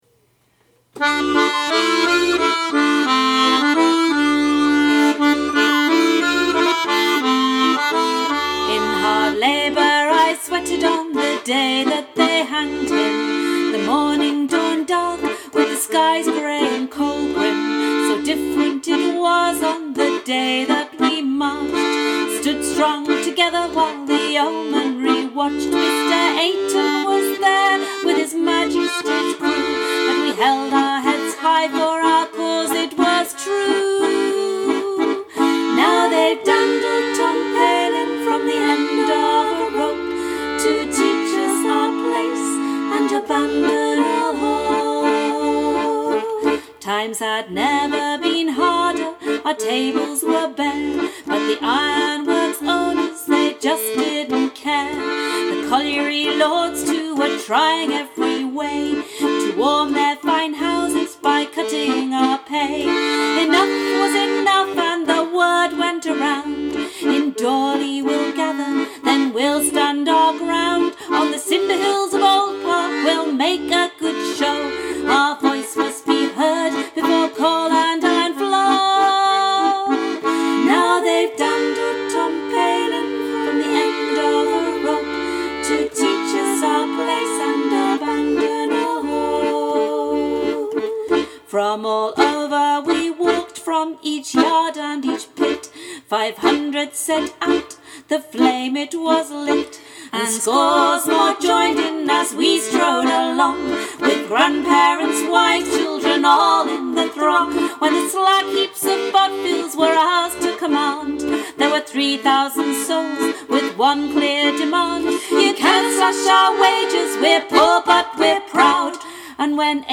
farewell-tom-slower-version.mp3